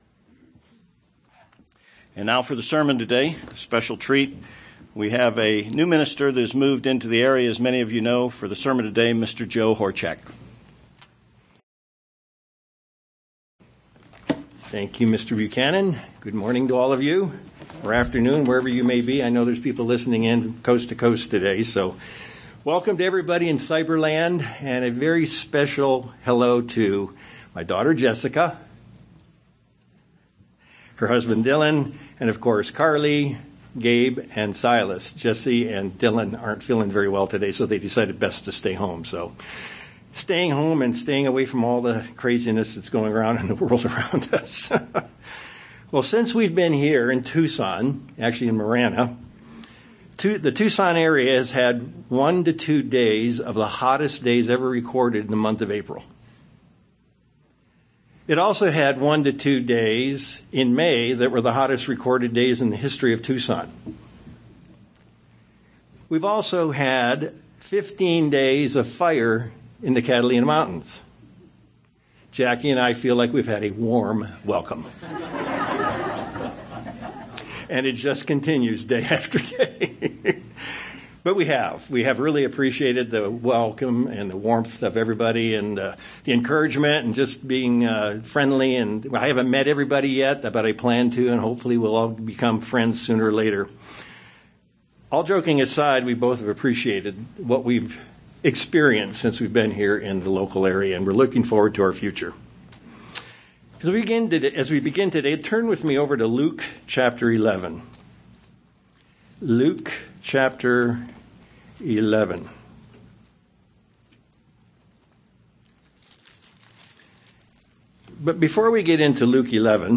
Sometimes we feel we're nagging, or not exercising faith and confidence, if we keep going back to Him in our prayers. This sermons examines whether that is accurate or not.